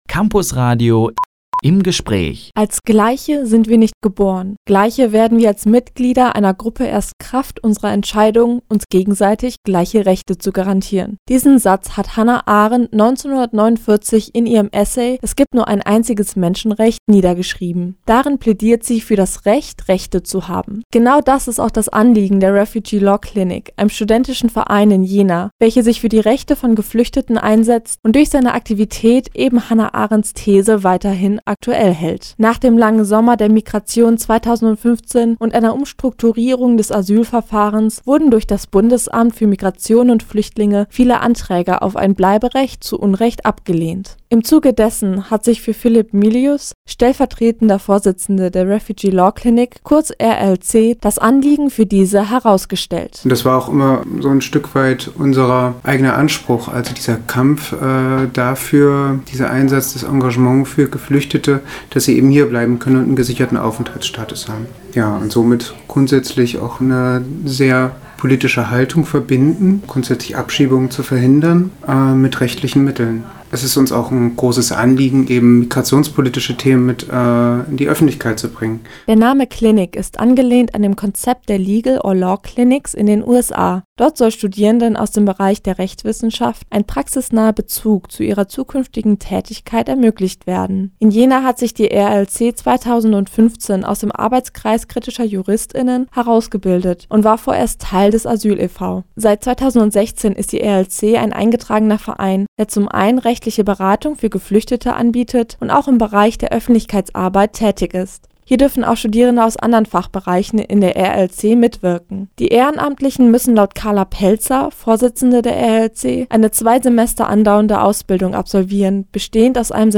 Im Gespräch: Refugee Law Clinic Jena – Campusradio Jena